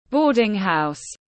Boarding house /ˈbɔː.dɪŋ ˌhaʊs/